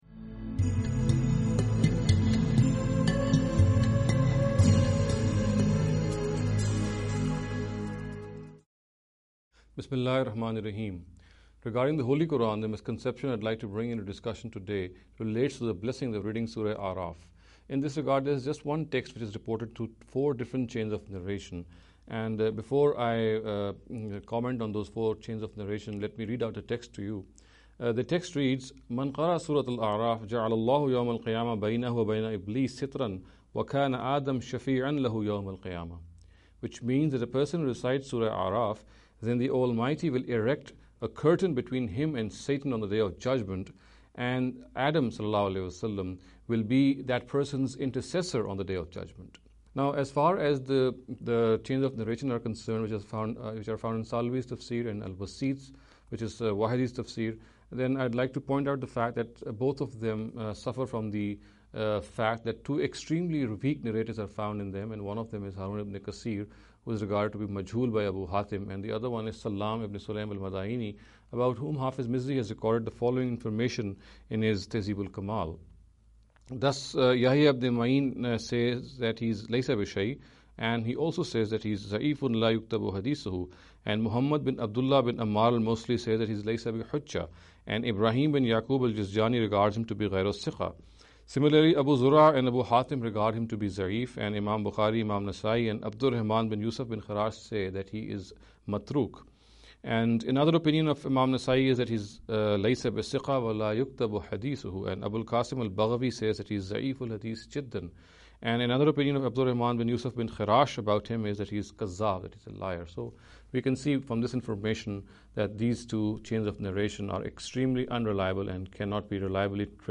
This lecture series will deal with some misconception regarding the Holy Quran.